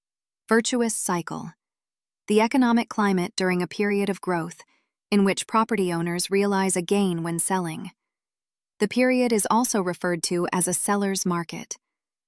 Listen to the terms you’ll need to remember most with an audio reading of definitions while you think through them.